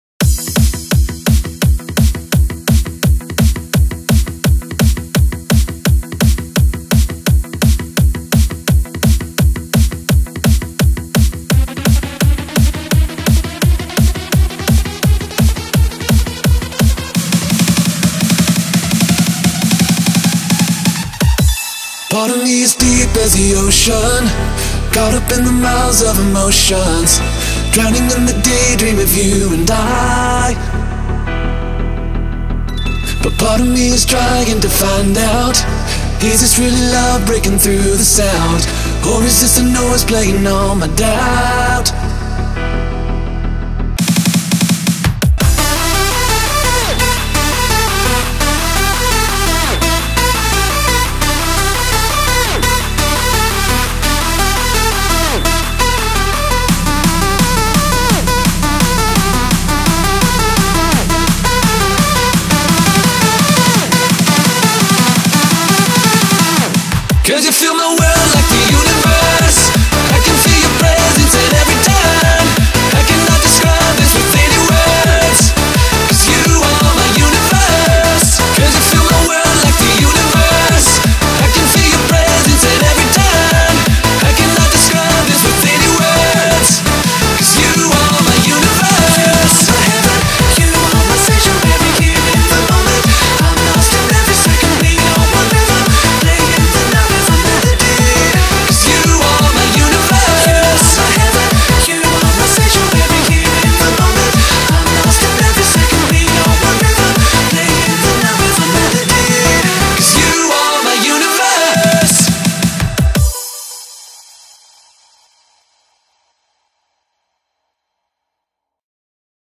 BPM85-170
MP3 QualityMusic Cut